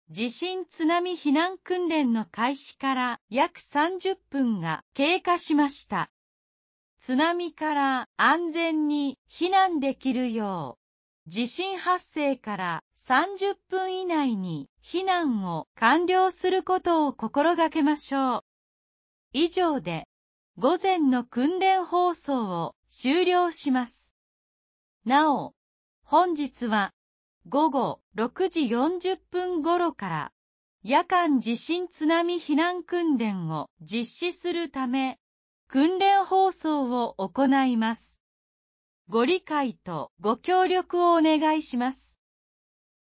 【訓練放送の終了】宇和島市地震津波避難訓練について | 宇和島市安心安全情報メール